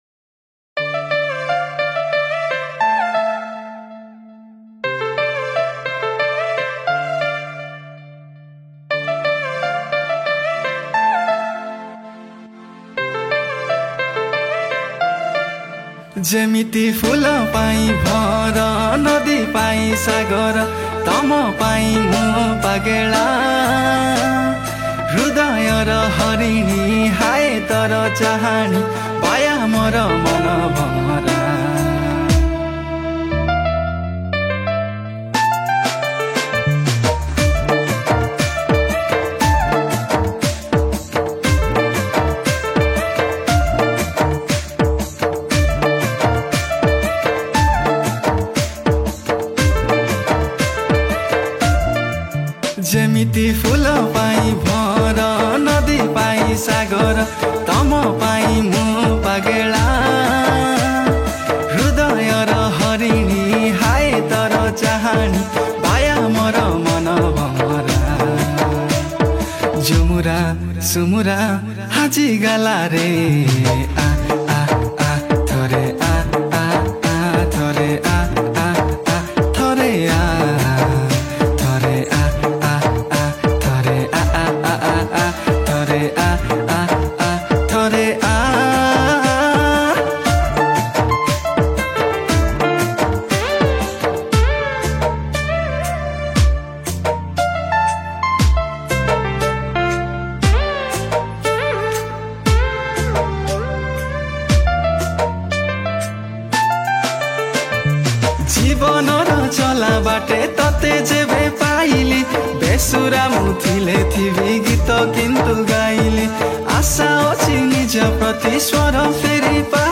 Category : Koraputia Song